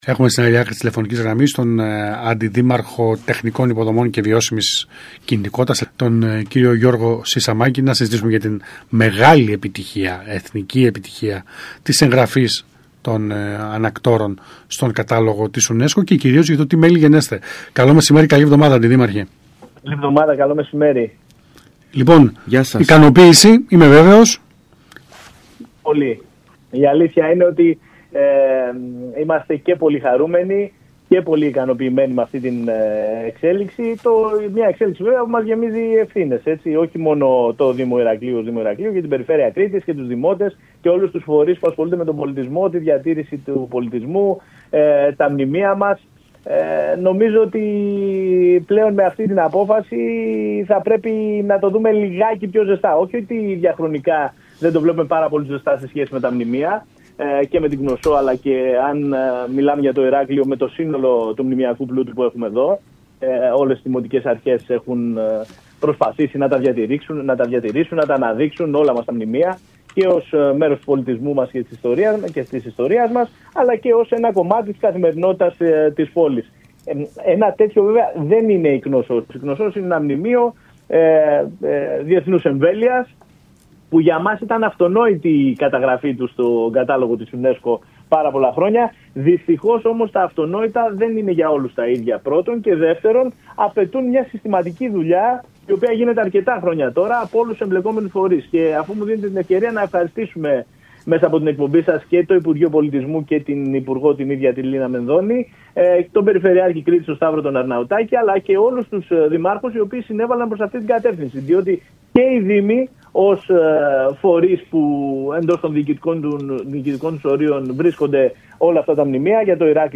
Ο κ. Σισαμάκης μίλησε το μεσημέρι της Δευτέρας 14 Ιουλίου στον ΣΚΑΙ Κρήτης 92.1